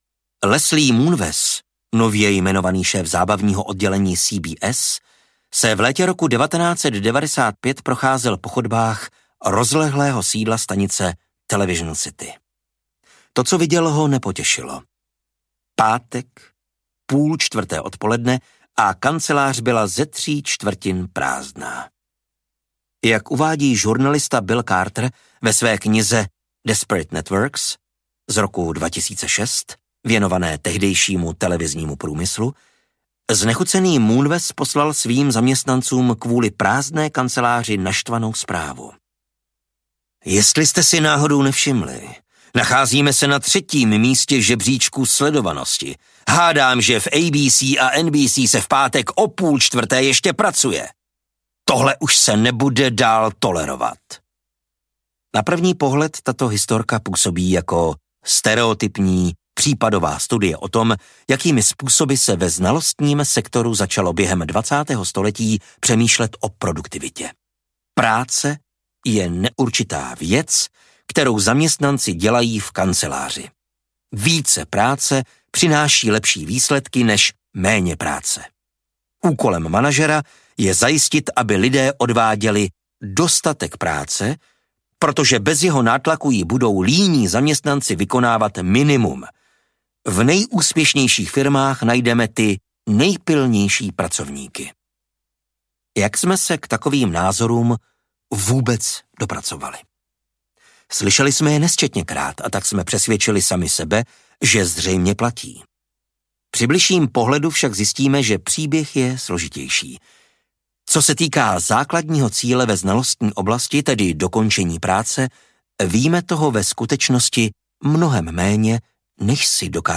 Audiokniha Pomalá produktivita - Cal Newport | ProgresGuru